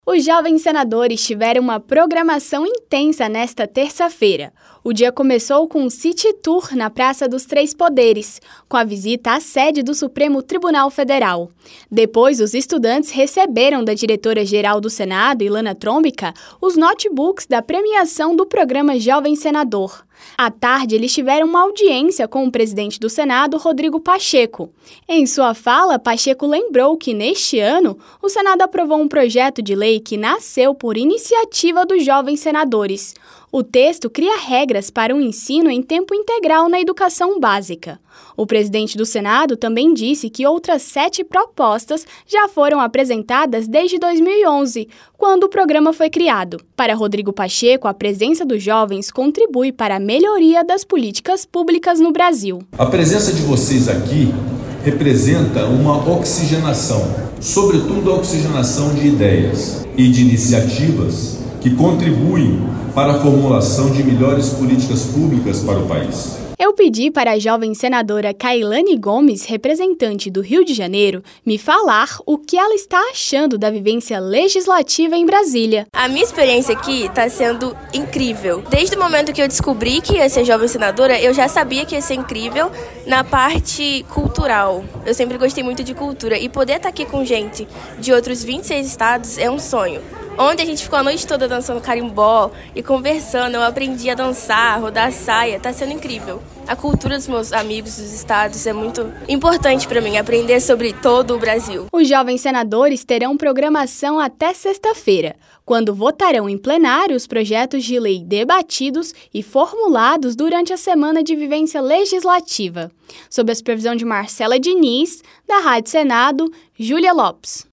Os 27 estudantes do Programa Jovem Senador 2024 foram recebidos nesta terça-feira (6) pelo presidente do Senado, Rodrigo Pacheco, que destacou em sua fala a importância da participação dos estudantes na formulação de políticas públicas para o país. O evento aconteceu no Salão Negro do Congresso Nacional.